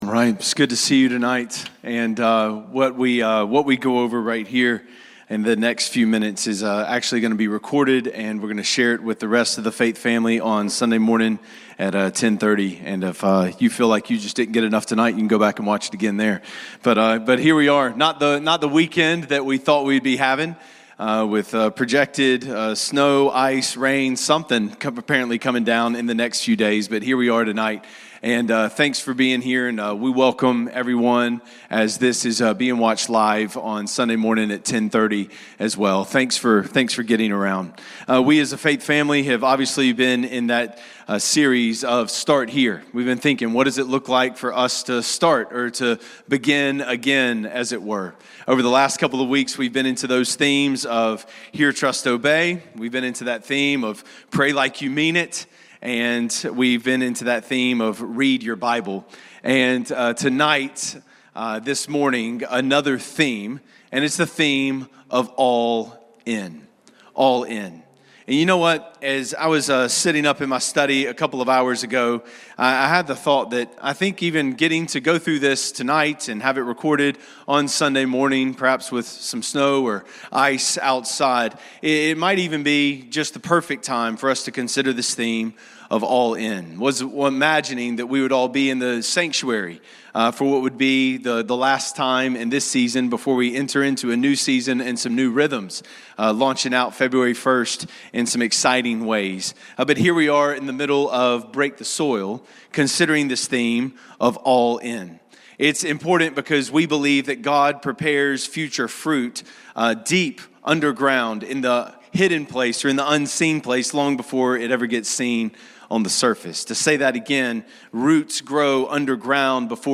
Note: This message was recorded Friday night and rebroadcast on Sunday due to weather conditions.